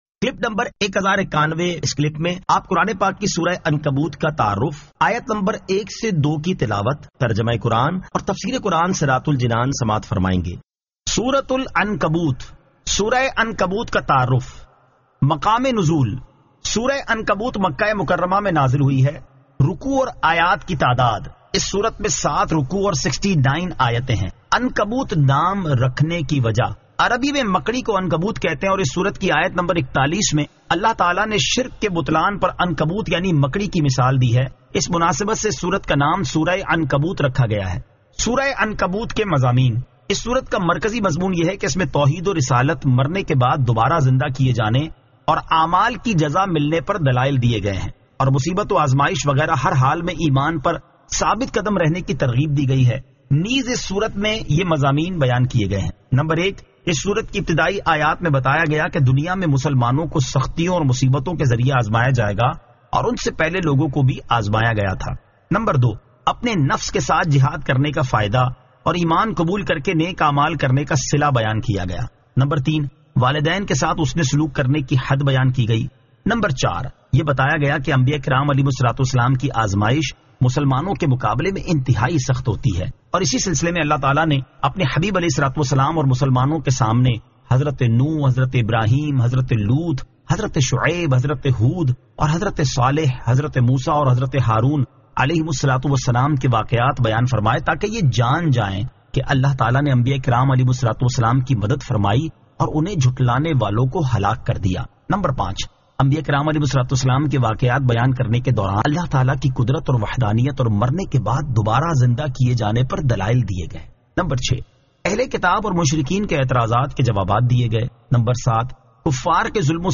Surah Al-Ankabut 01 To 02 Tilawat , Tarjama , Tafseer